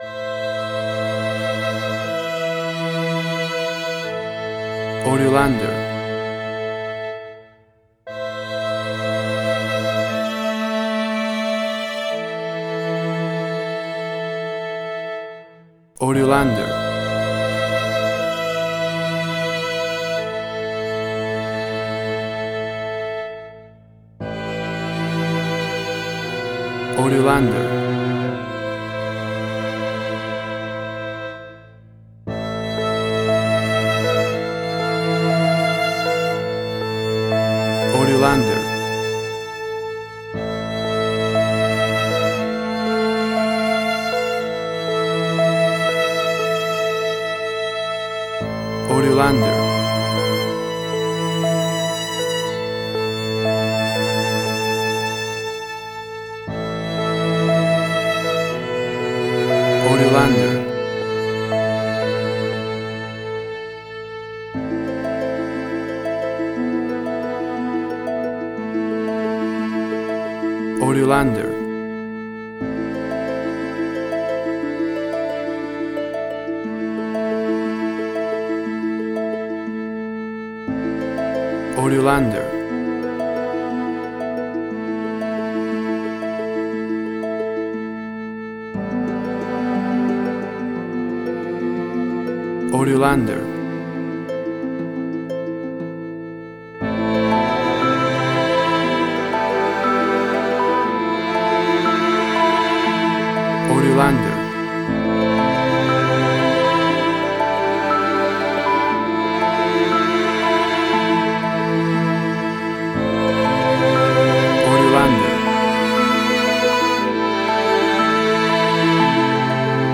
Suspense, Drama, Quirky, Emotional.
Tempo (BPM): 60